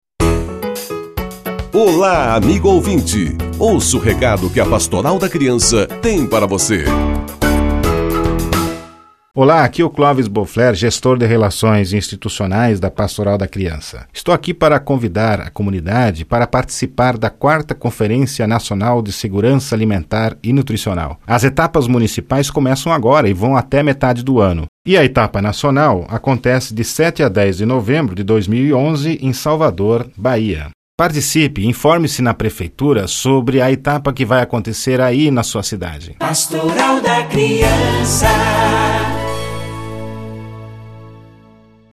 Spot de rádio: Chamada para a 4ª Conferência produzida pela Pastoral da Criança